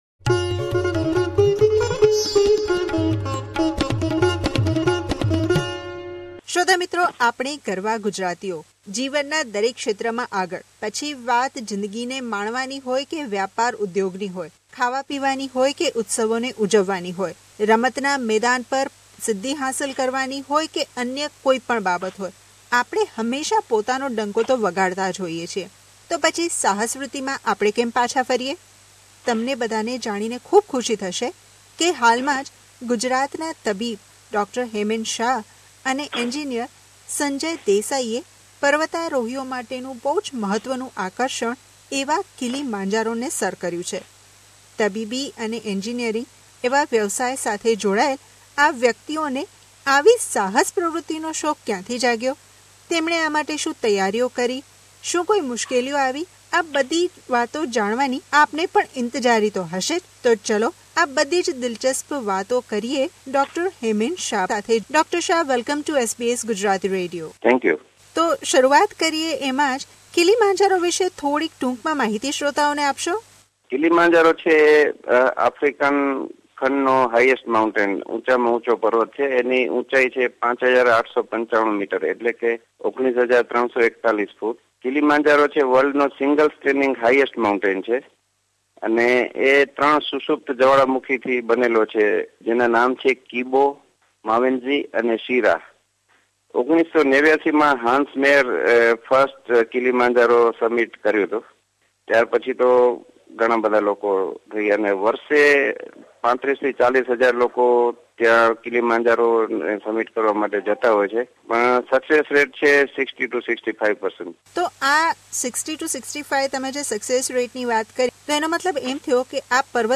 વાતચીત